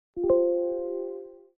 • Качество: 320, Stereo
без слов
короткие
простые